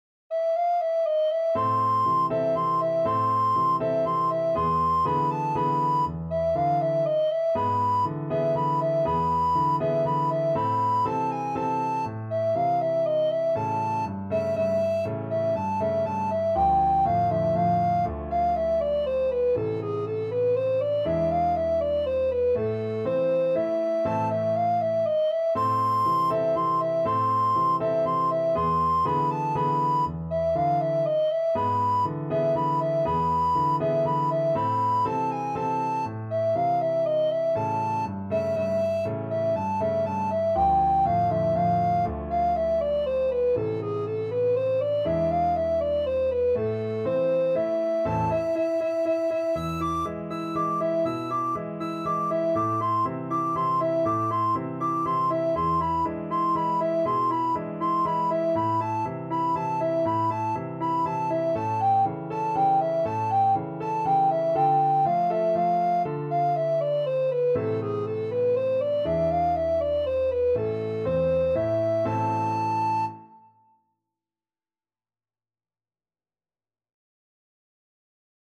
Alto Recorder
A minor (Sounding Pitch) (View more A minor Music for Alto Recorder )
3/4 (View more 3/4 Music)
Venezuelan